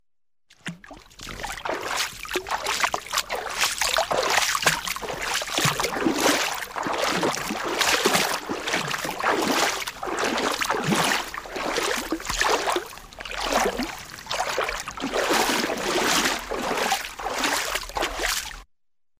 Шаги по пояс в воде
Тут вы можете прослушать онлайн и скачать бесплатно аудио запись из категории «Шаги, бег, движение».